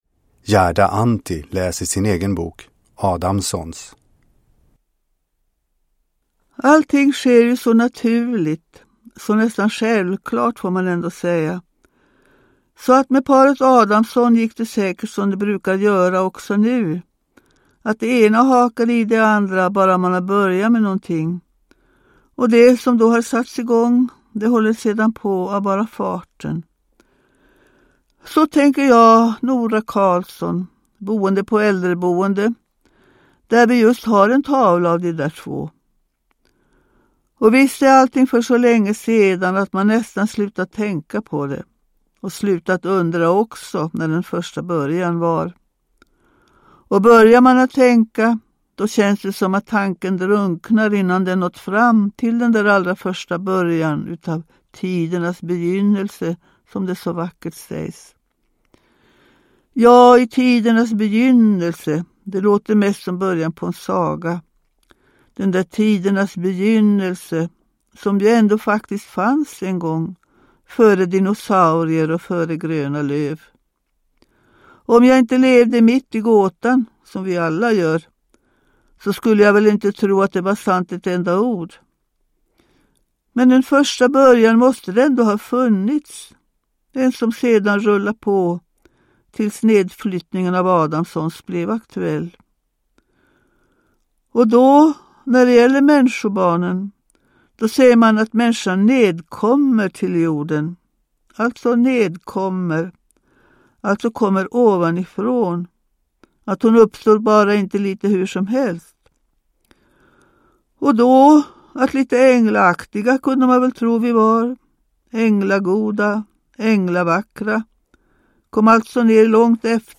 Uppläsare: Gerda Antti
Ljudbok